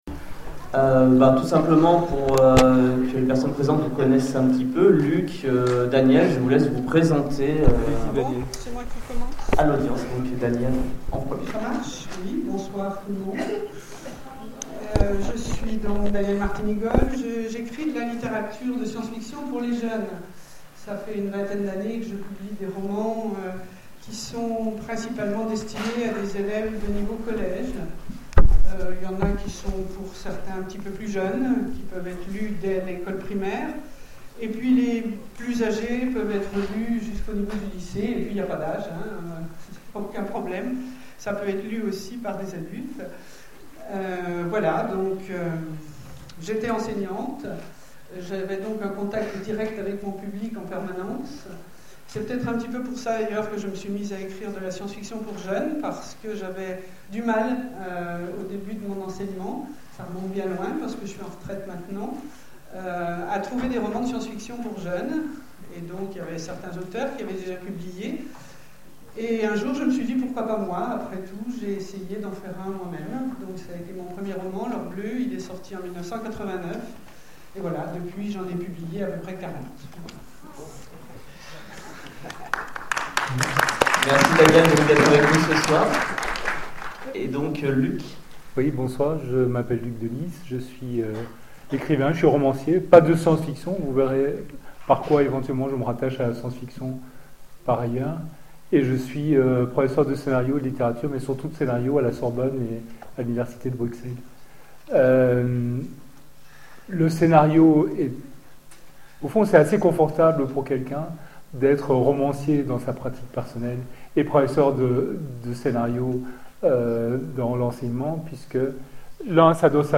Nuit de la science fiction à Oullins : Débat science fiction et religion
Conférence